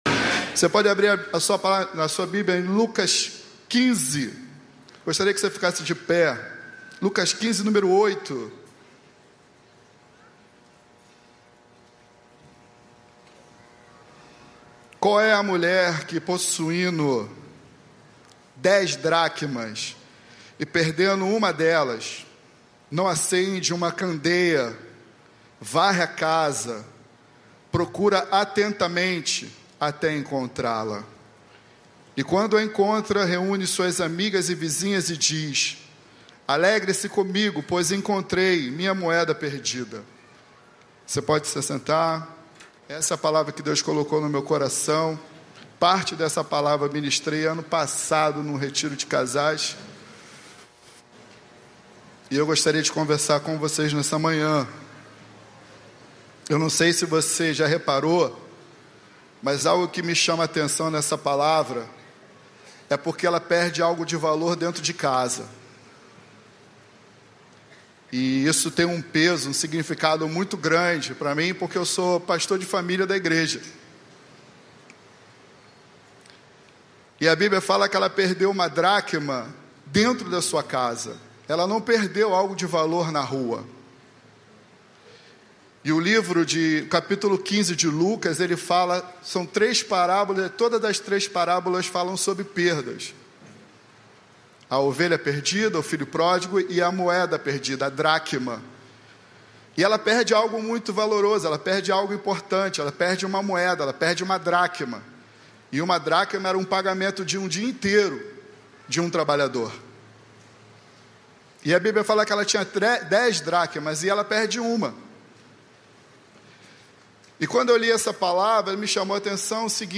Igreja Batista do Recreio